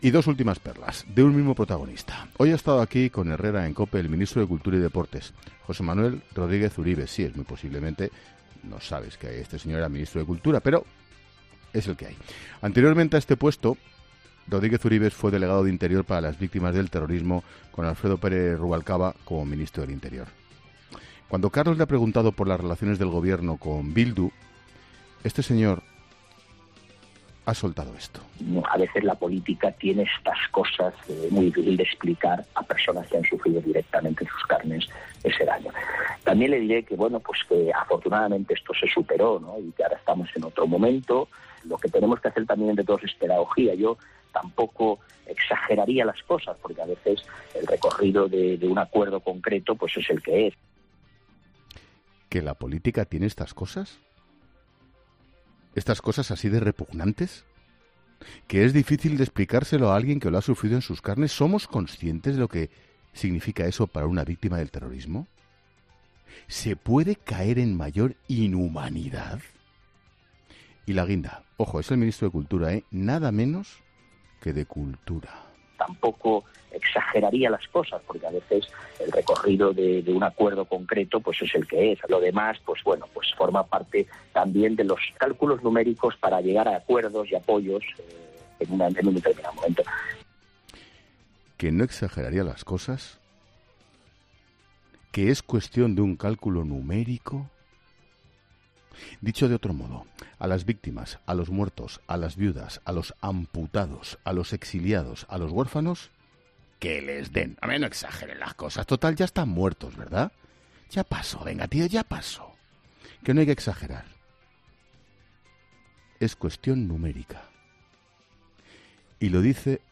Ángel Expósito ha aprovechado este jueves su monólogo inicial de ‘La Linterna’ para criticar con dureza al ministro de Cultura y Deporte, José Manuel Rodríguez Uribes.
Al oír esto, Expósito no ha podido ocultar su indignación.